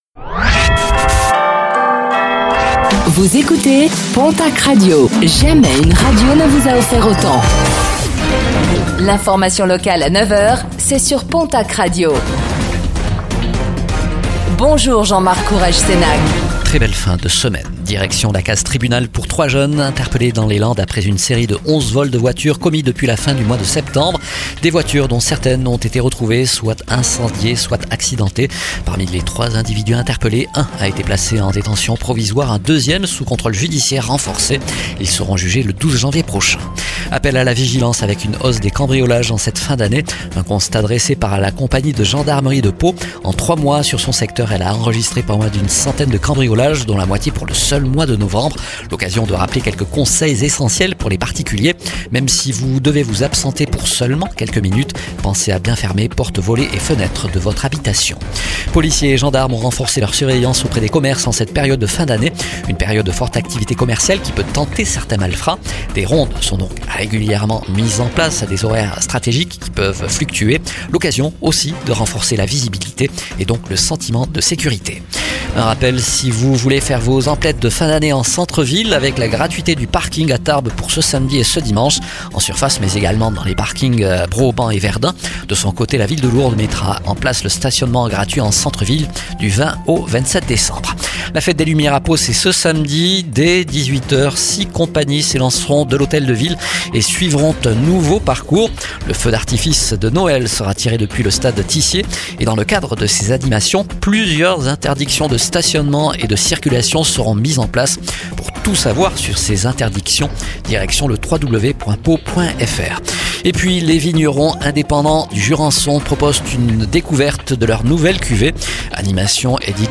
Réécoutez le flash d'information locale de ce vendredi 12 décembre 2025